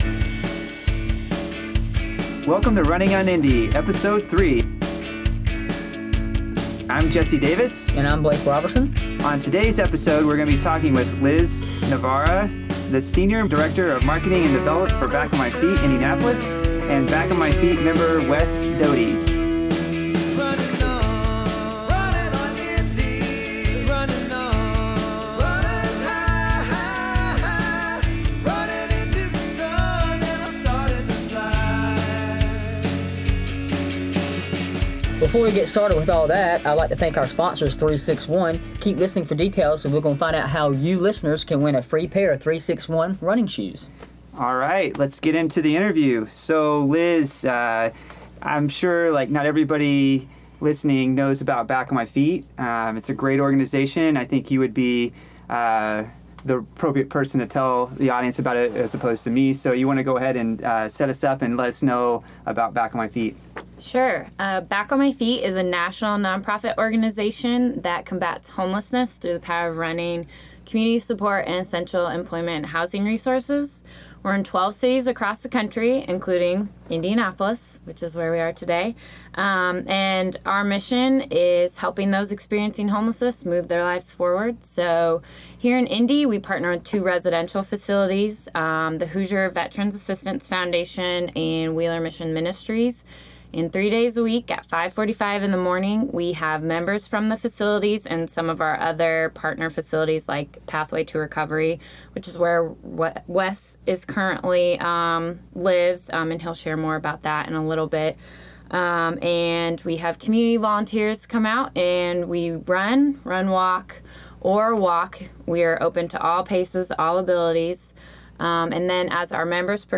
In Episode 3 we have a frank conversation